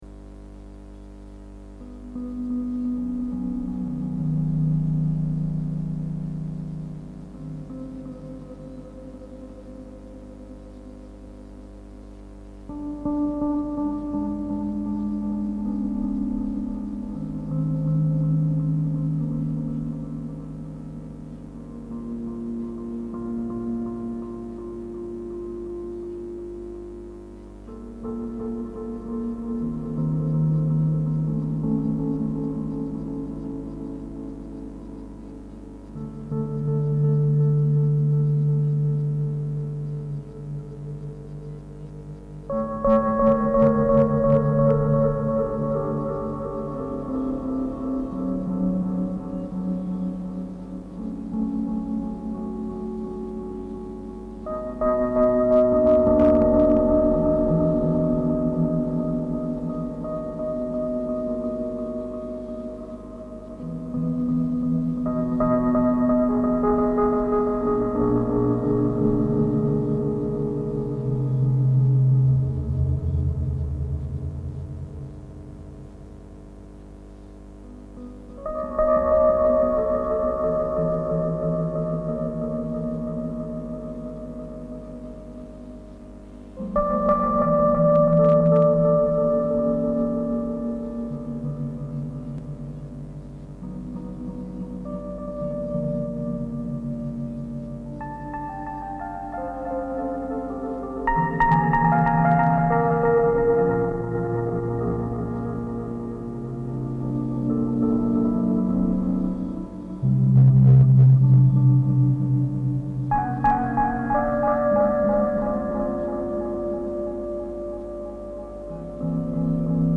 rhodes and wurlitzer pianos, organ, tapes, Roland Sh-101
organ
bass clarinet
tenor saxophone/clarinet
drums